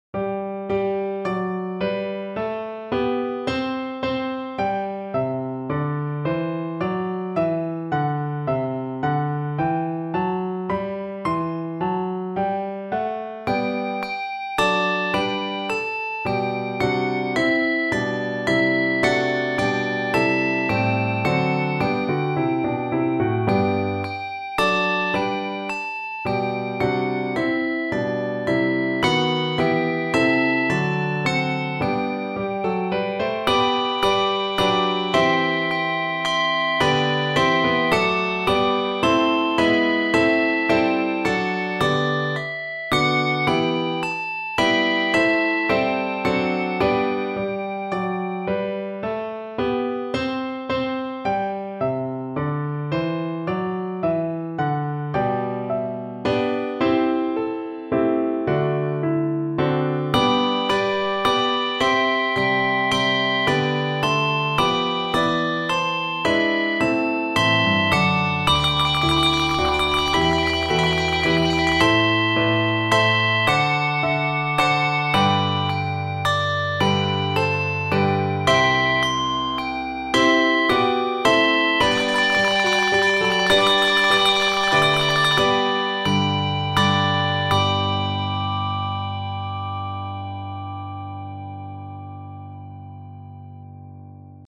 hymn arrangements
for 8 handbells or handchimes and piano